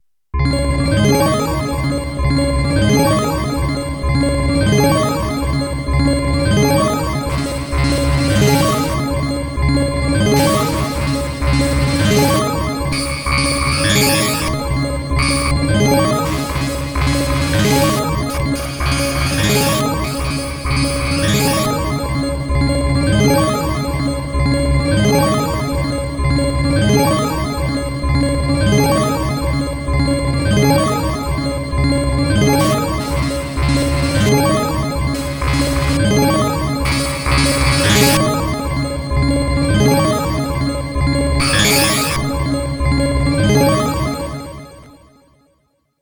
I attempted to mod it with a circuit bending, but no great results, some exemple audio clips in the DEMO section